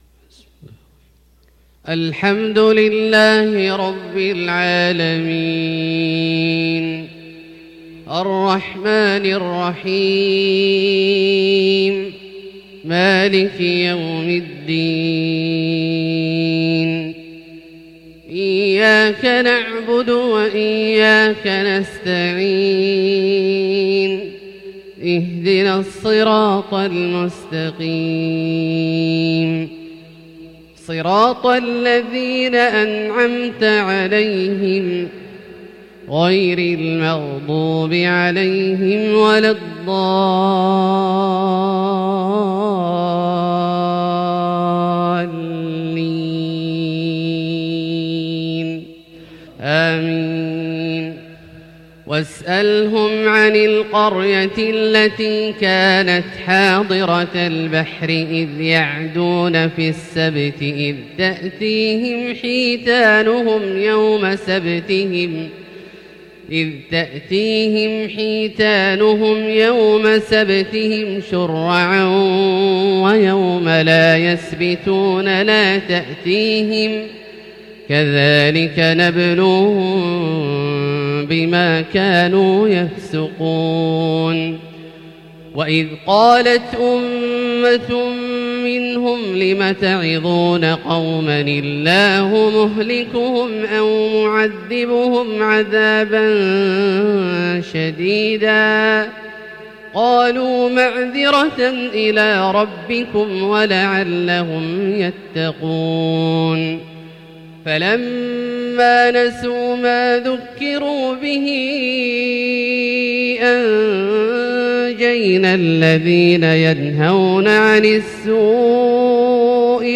Fajr 2-2-2021 from surat Al-Araf > H 1442 > Prayers - Abdullah Al-Juhani Recitations